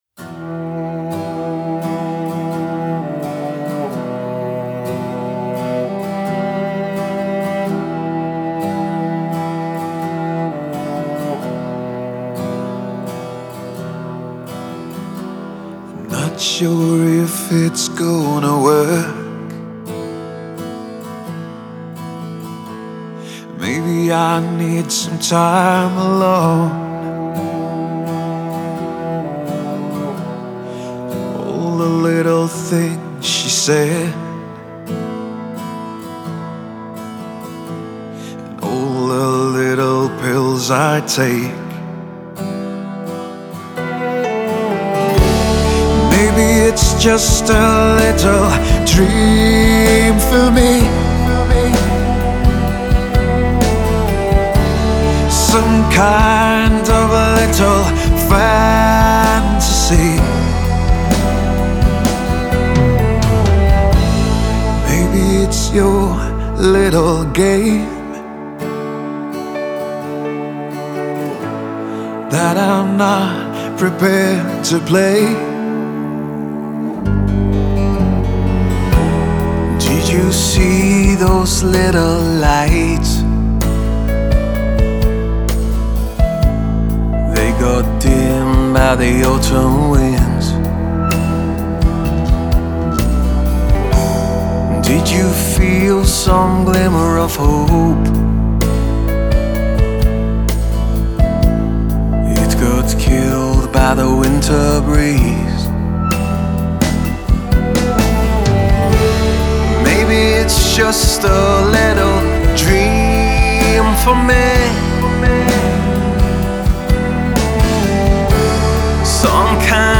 آهنگ راک
Melancholic ملنکولیک Dark rock music